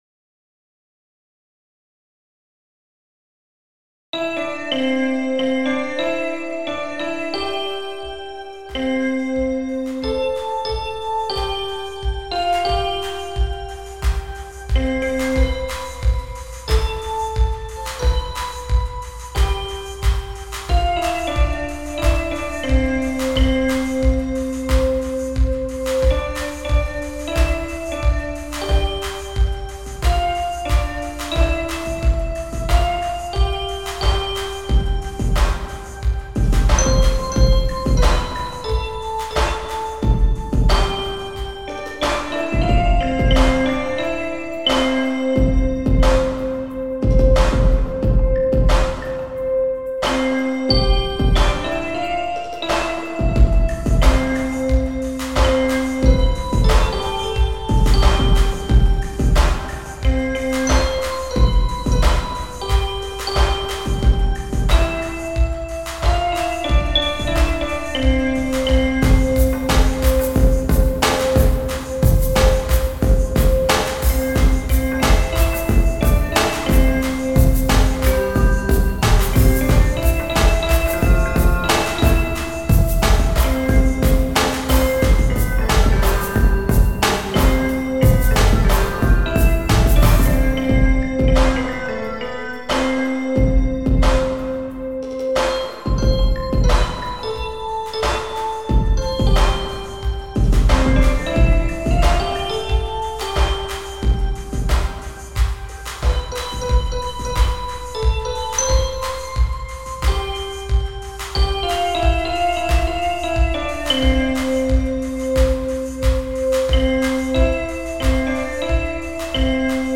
Posted in Dubstep Comments Off on